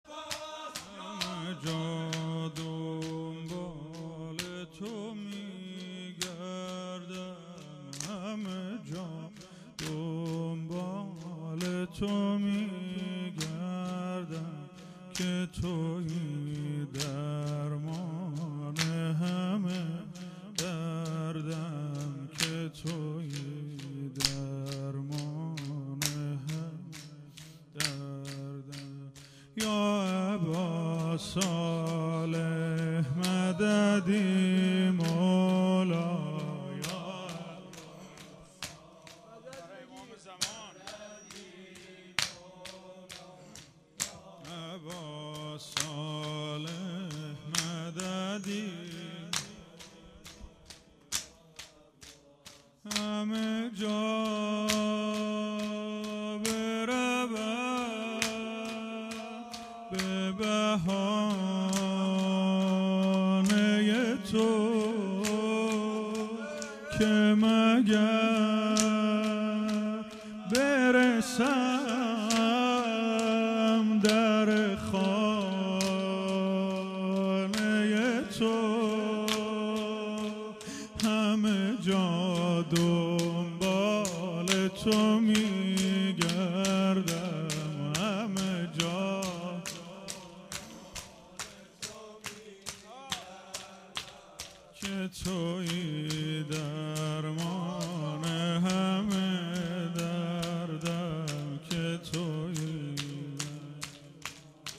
مداحی چهارضرب پایانی
مراسم هفتگی و وفات حضرت ام البنین(سلام الله علیها)17بهمن1398